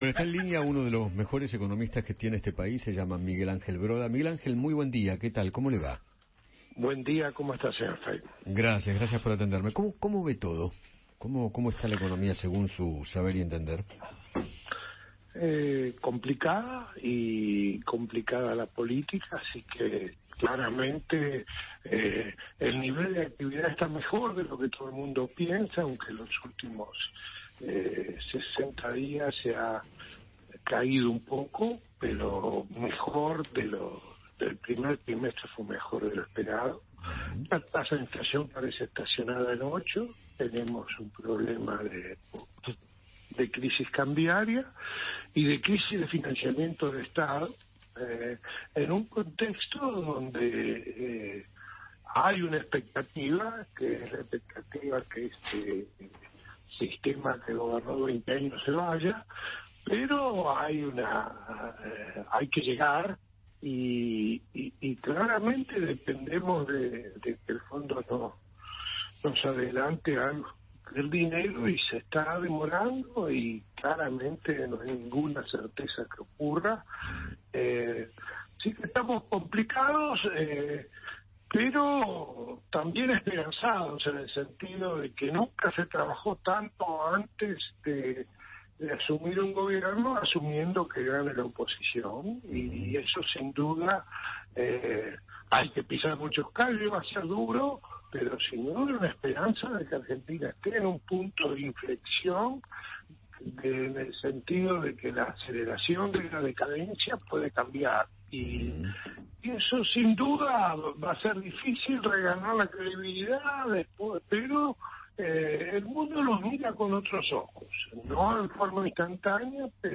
El economista Miguel Ángel Broda conversó con Eduardo Feinmann sobre la coyuntura del país y analizó la influencia en las elecciones 2023.